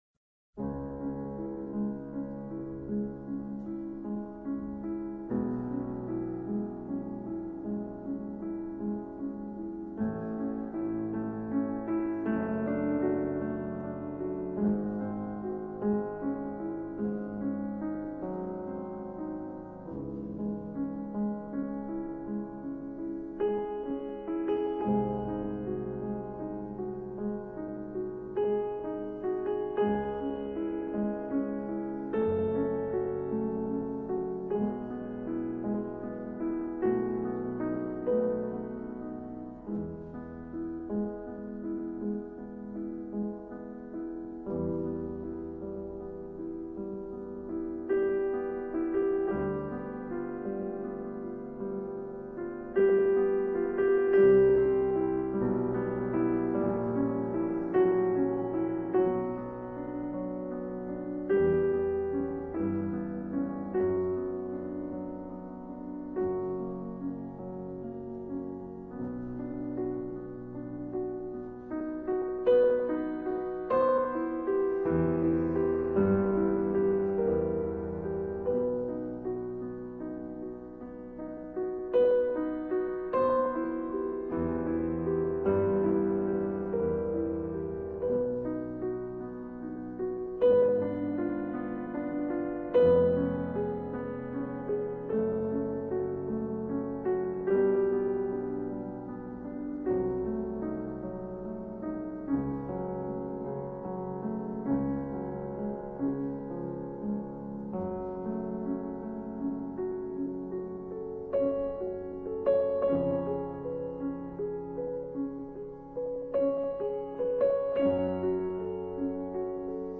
MUSICA CLASICA RELAX.mp3